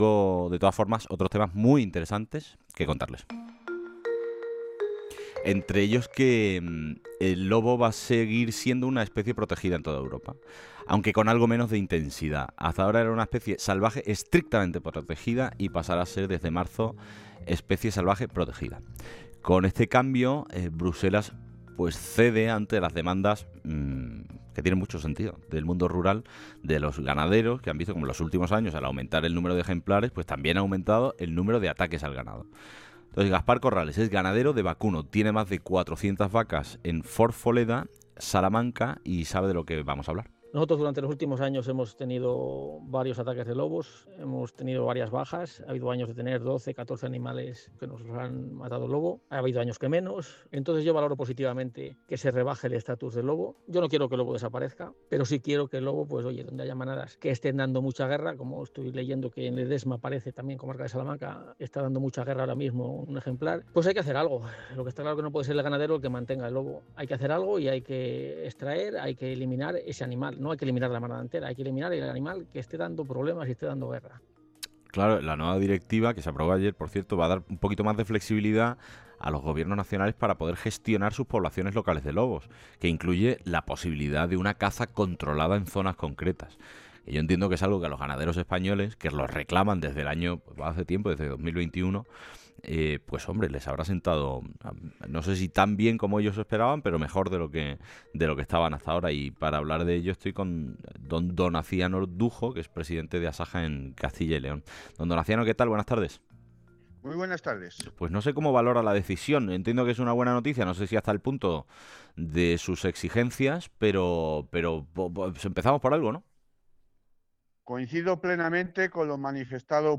en el programa Herrera en COPE, emitido el pasado 10 de diciembre.